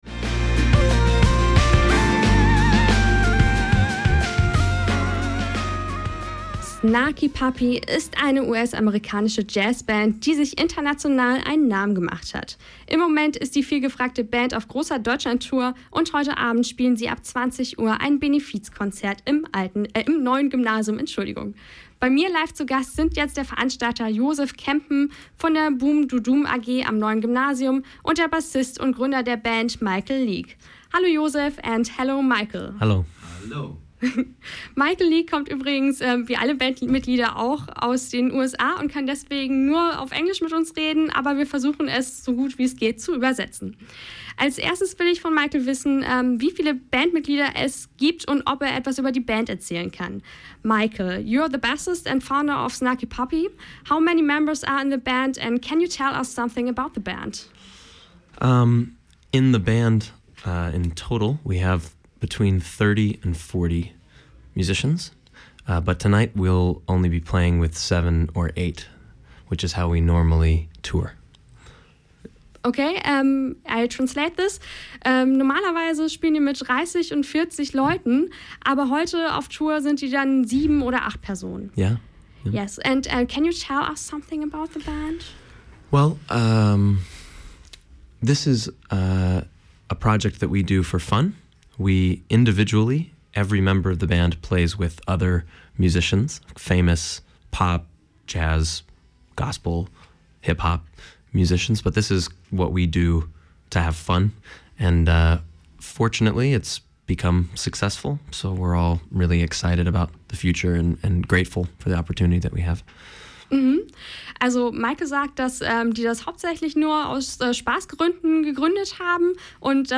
Das Studiogespräch mit Michael League
im Lokalsender oeins